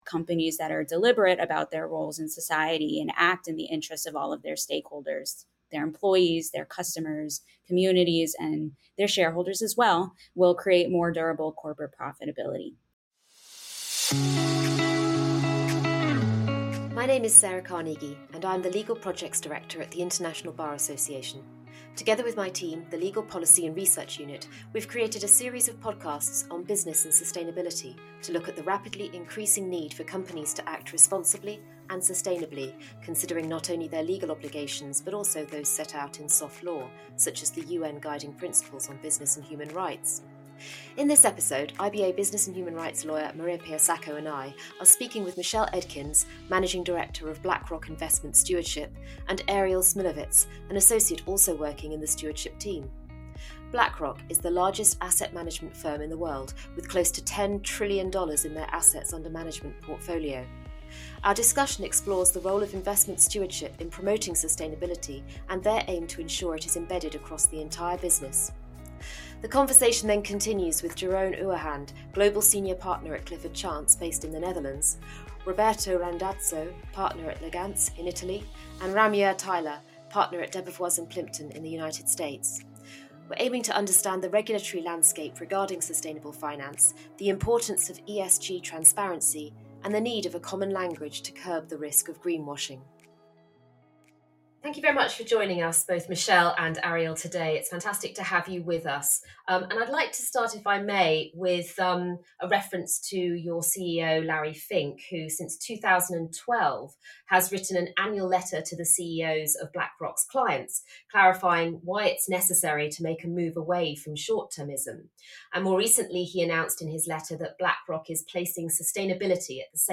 Sustainable finance – interview with BlackRock and experts from private practice